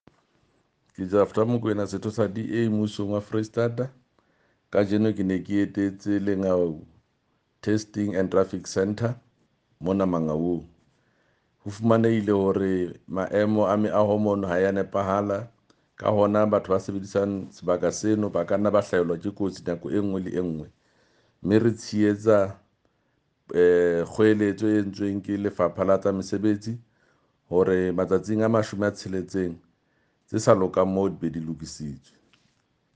Sesotho soundbites by Jafta Makoena MPL.